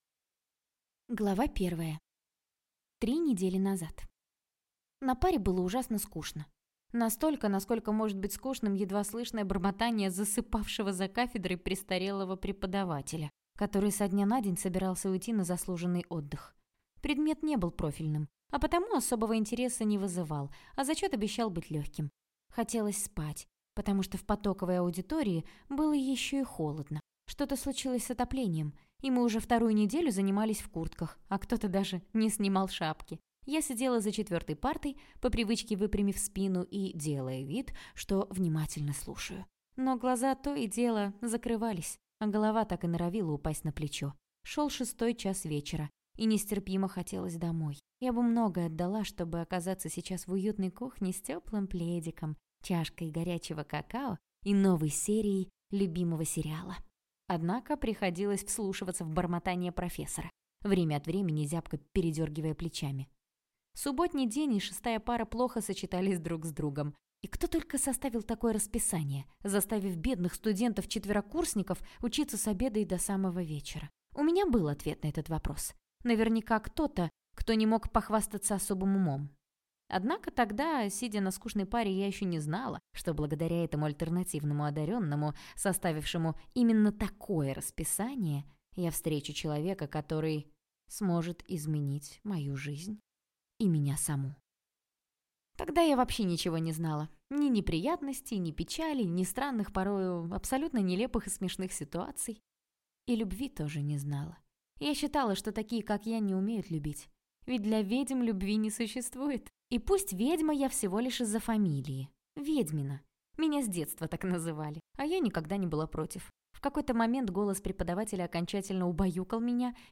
Аудиокнига Восхитительная ведьма - купить, скачать и слушать онлайн | КнигоПоиск